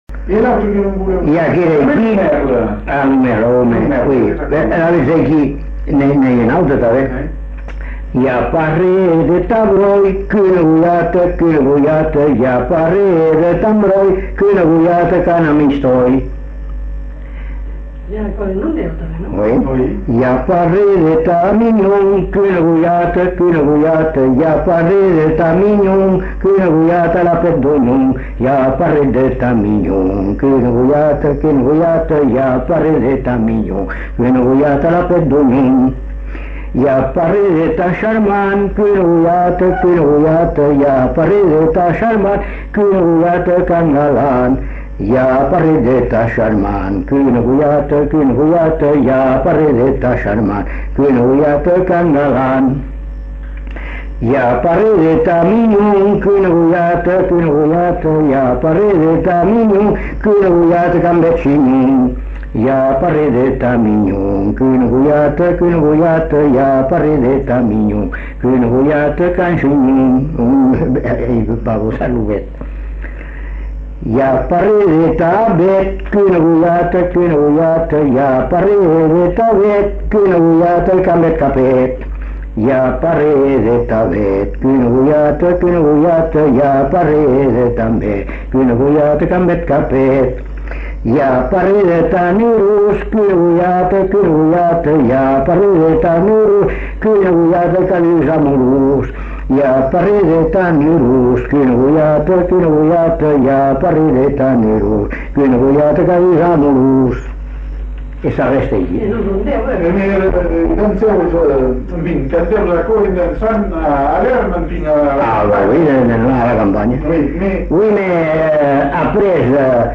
Aire culturelle : Bazadais
Genre : chant
Effectif : 1
Type de voix : voix d'homme
Production du son : chanté
Danse : rondeau